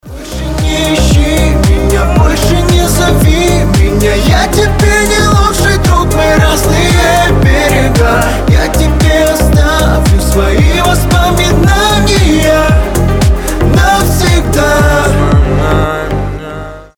мужской вокал